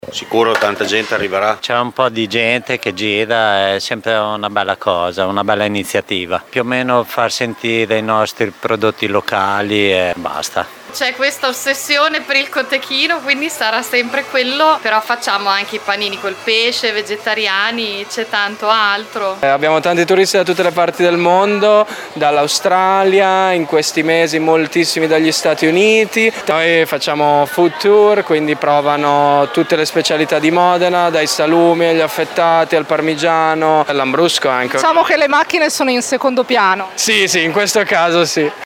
Le interviste ai protagonisti:
Soddisfatti baristi e ristoratori